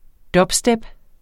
Udtale [ ˈdʌbˌsdεb ]